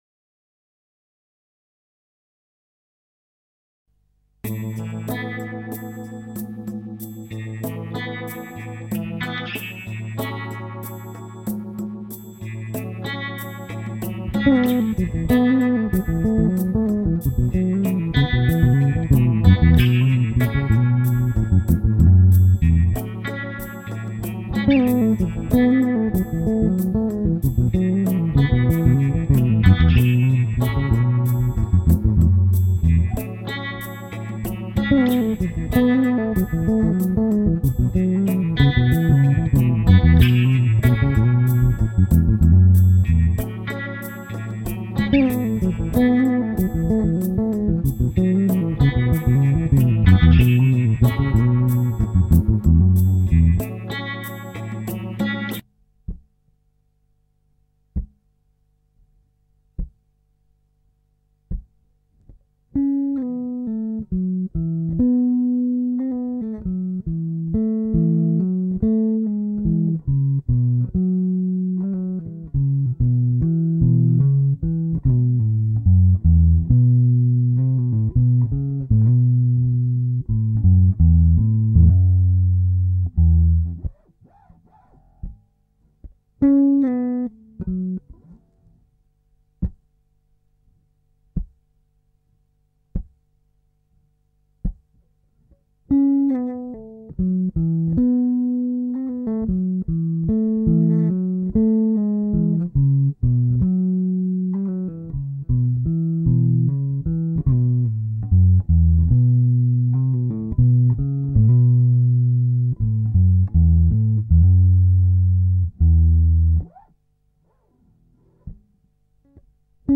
L284 A Major bass lick